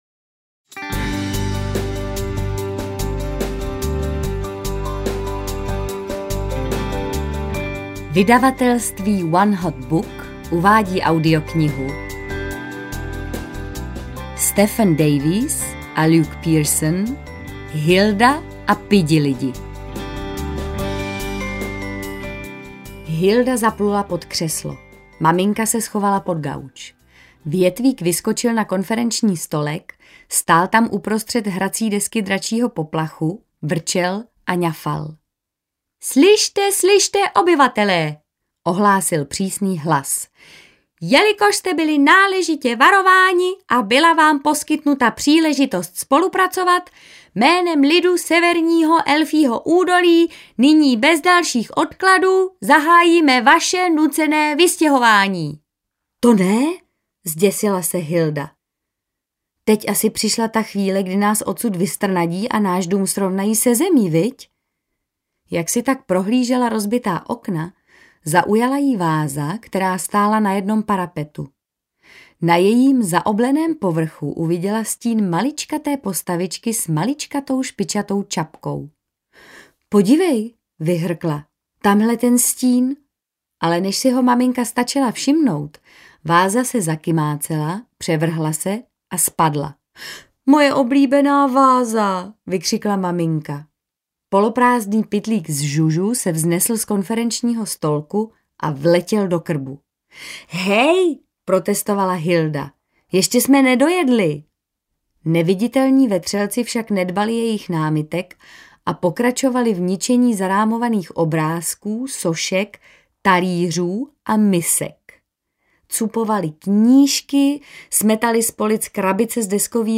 Hilda a pidilidi audiokniha
Ukázka z knihy
• InterpretMartha Issová